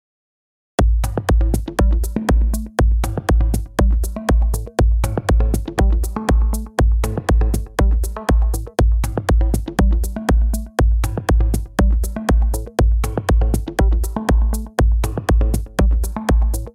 ウワものは（ハイハットやパーカッションなど）ループ素材。
今回のデモ曲は、テクノで攻めようと思います。
このリズムにシンセを乗せてみよう。
ArpeggioのDotted Chords JH。めっちゃ良い音色。